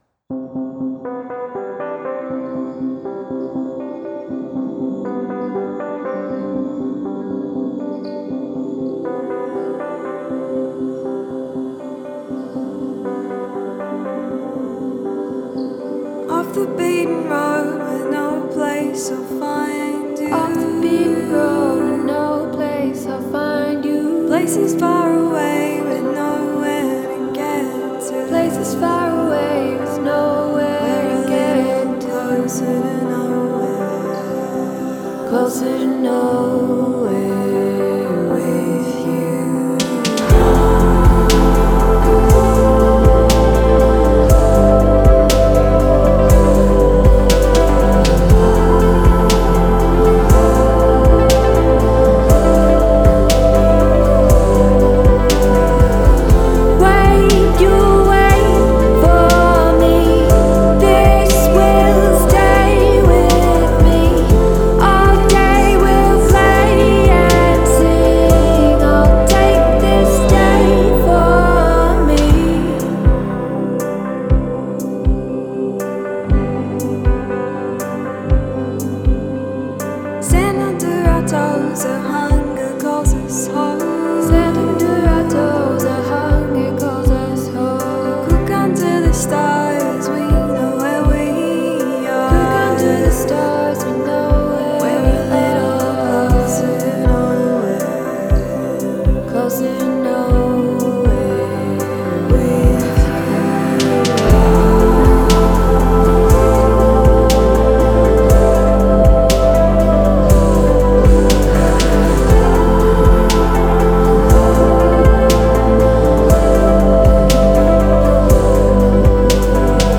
le jeune trio australien